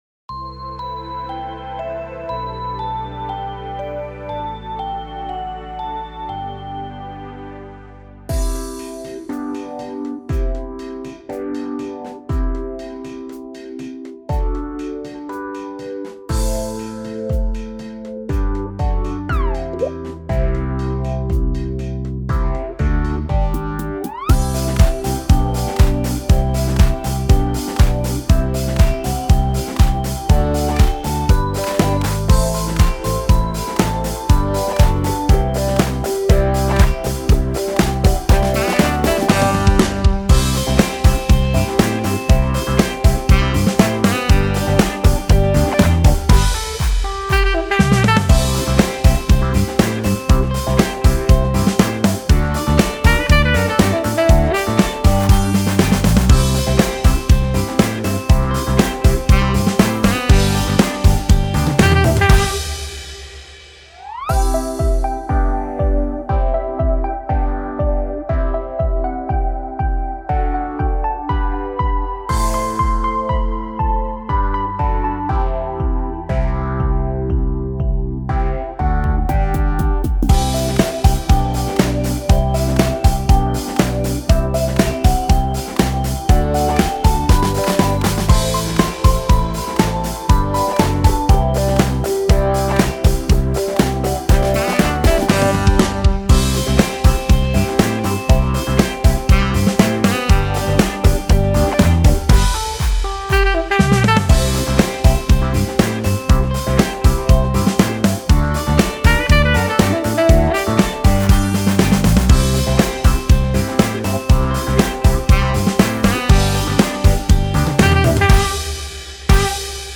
Karaoke versija